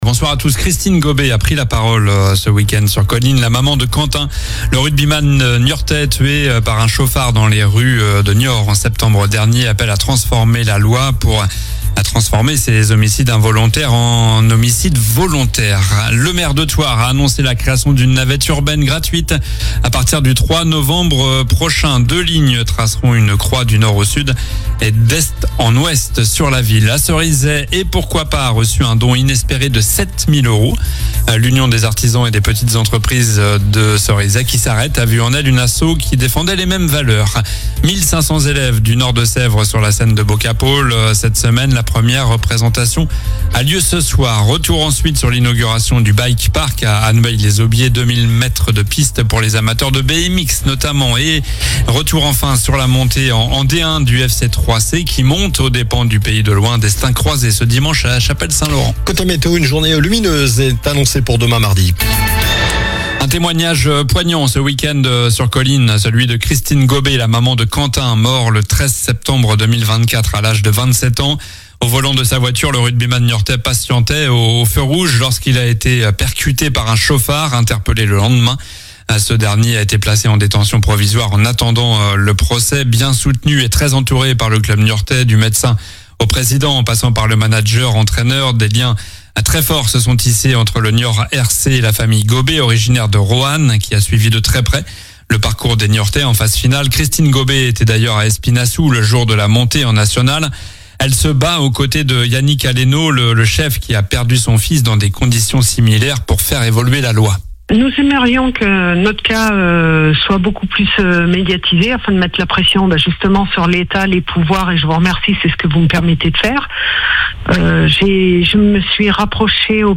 Journal du lundi 19 mai (soir)